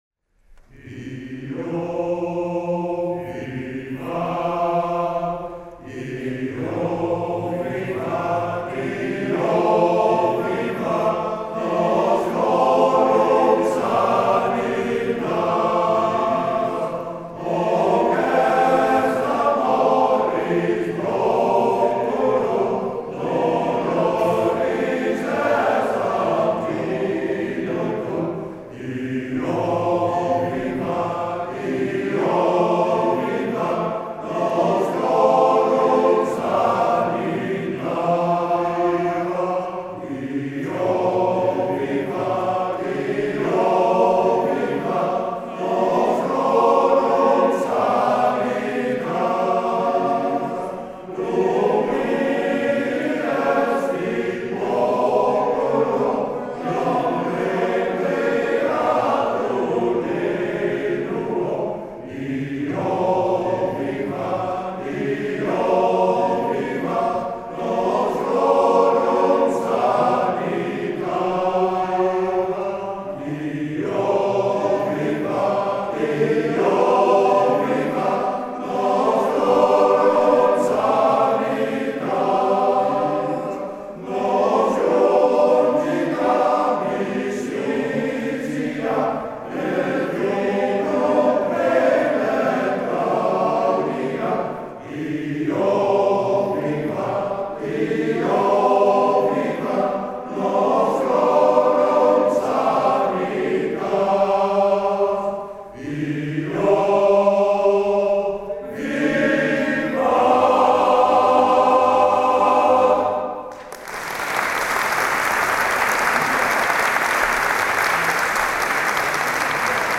ROSMALENS MANNENKOOR
Internationaal Studentenlied